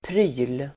Uttal: [pry:l]